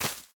Minecraft Version Minecraft Version latest Latest Release | Latest Snapshot latest / assets / minecraft / sounds / block / cave_vines / break4.ogg Compare With Compare With Latest Release | Latest Snapshot